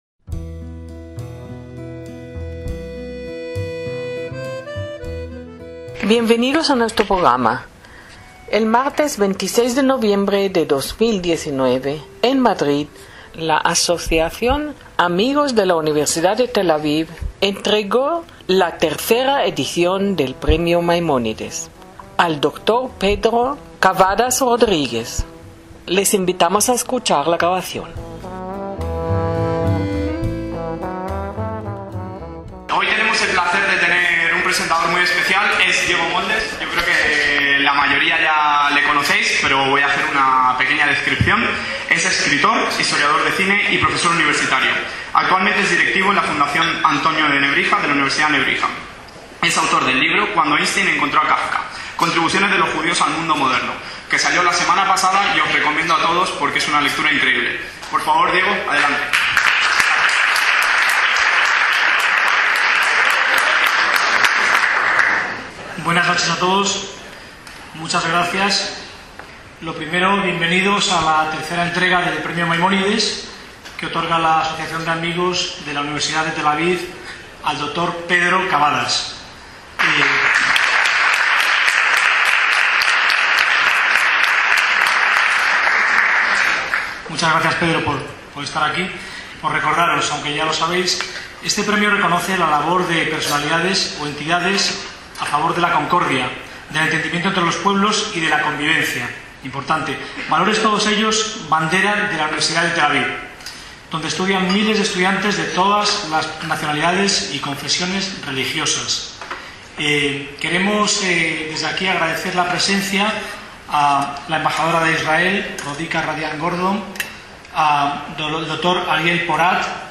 Ceremonia de entrega del 3º Premio Maimónides (Hotel Intercontinental, Madrid, 26/11/2019)